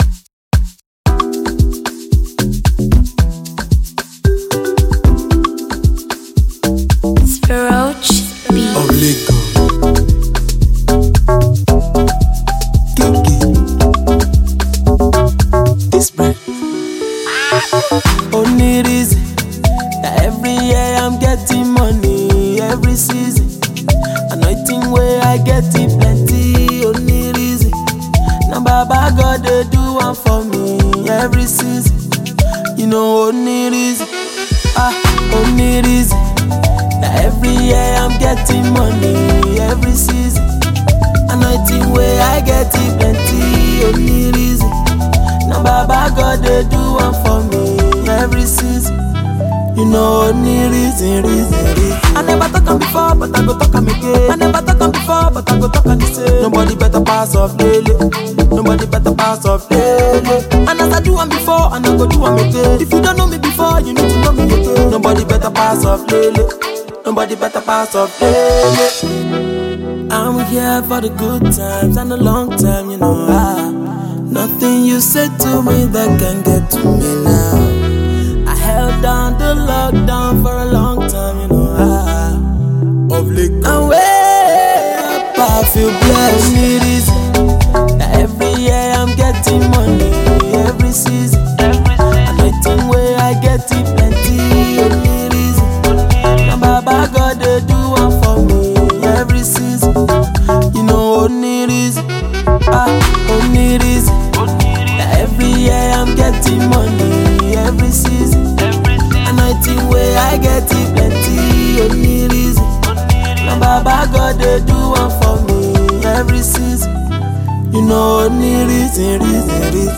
A-List Nigerian singer and songwriter